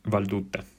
Didascalie italiano Aggiungi una brevissima spiegazione di ciò che questo file rappresenta francese Prononciation du toponyme de la région autonome Vallée d'Aoste (Italie) en francoprovençal (arpitan) valdôtain